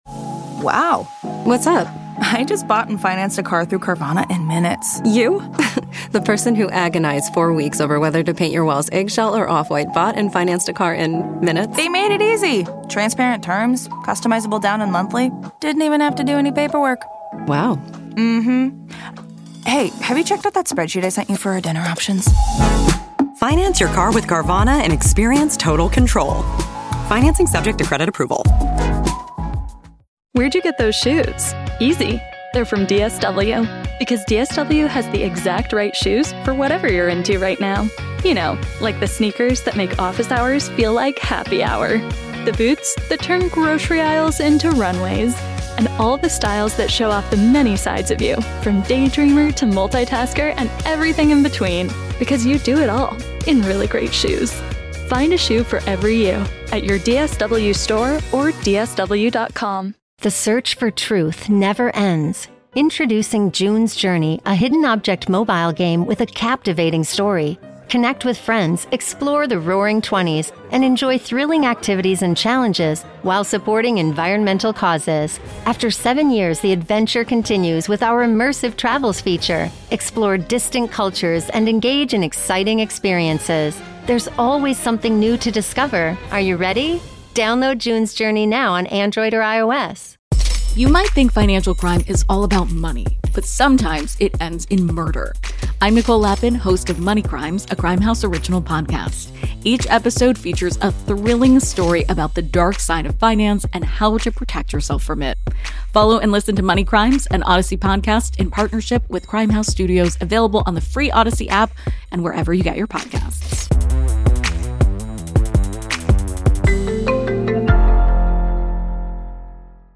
Three local, Las Vegas friends discuss life, current events, and everything else that pops into their heads.